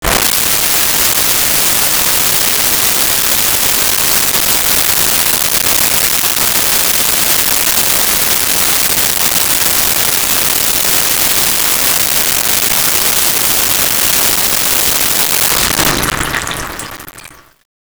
Hand Held Bidet 1
hand-held-bidet-1.wav